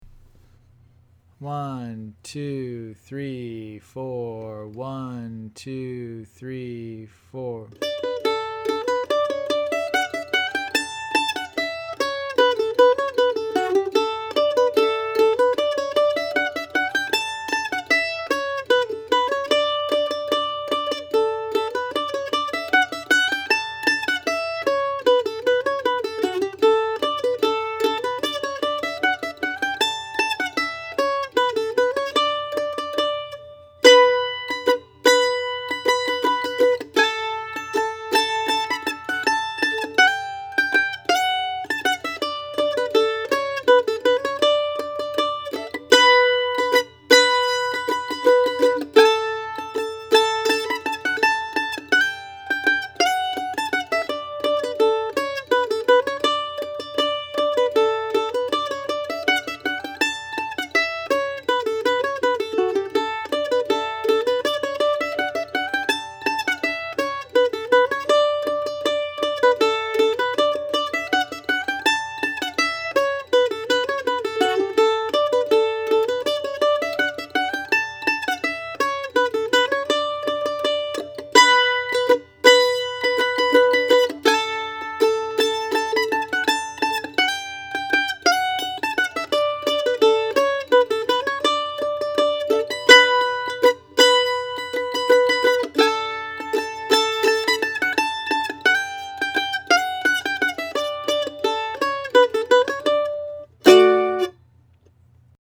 OTMM Lesson - Oklahoma Rooster